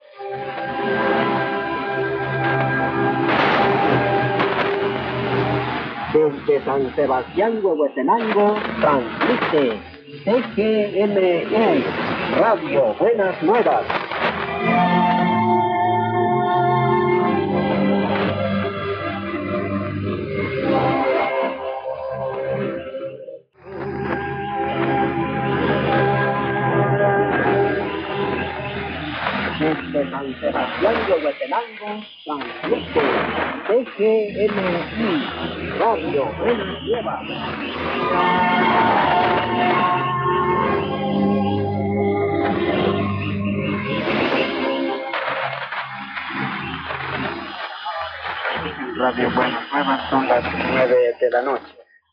in Alberta, Canada on 23 March 1992 at 0300 UTC: